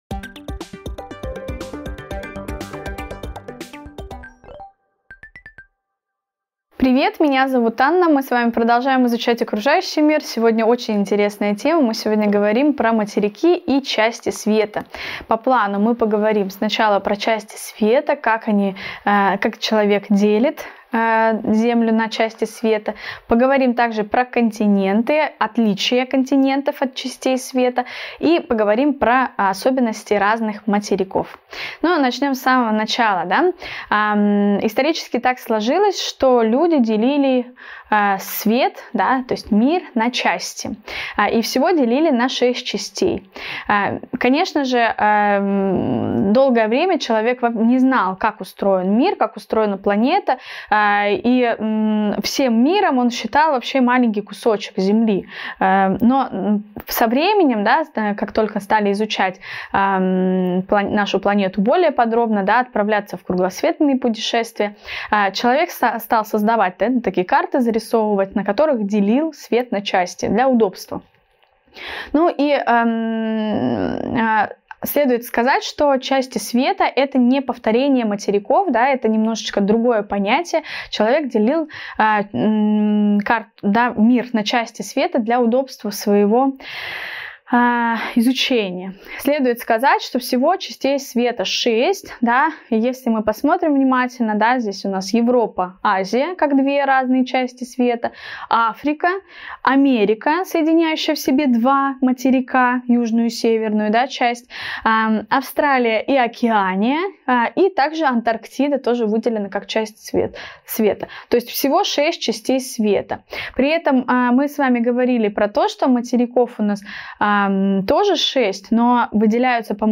Видеоурок 32 Материки и части света Окружающий мир 2 клас�